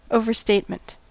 Ääntäminen
Synonyymit hyperbole exaggeration hyperbolism Ääntäminen US : IPA : [ˈoʊ.vɚ.steɪt.mənt] Haettu sana löytyi näillä lähdekielillä: englanti Käännöksiä ei löytynyt valitulle kohdekielelle.